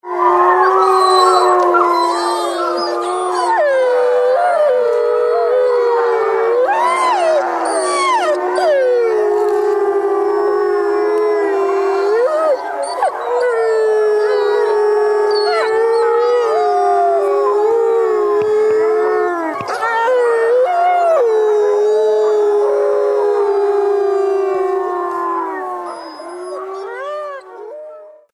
howlingWolves howling.
Wolves.mp3